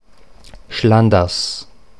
Standarddeutsche Form
[ˈʃlandәrs]
Schlanders_Standard.mp3